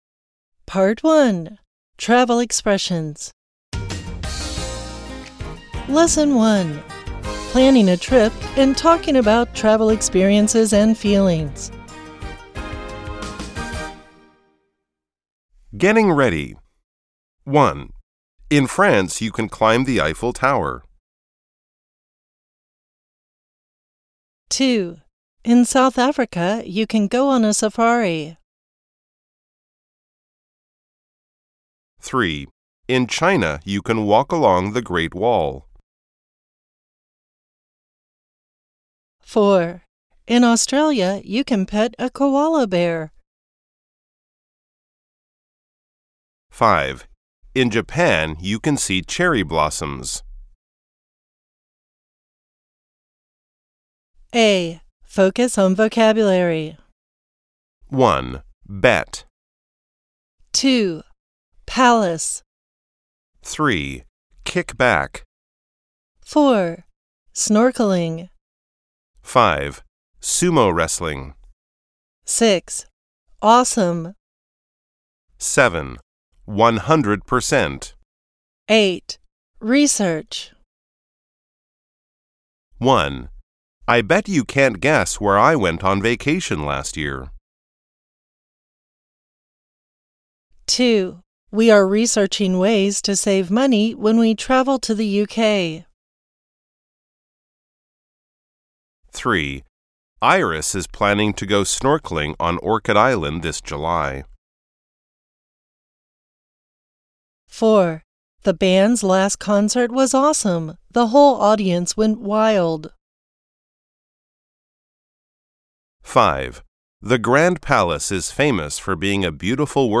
English Speaking and Listening Practice
掃描書封QR Code下載「寂天雲」App，即能下載全書音檔，無論何時何地都能輕鬆聽取專業母語老師的正確道地示範發音，訓練您的聽力。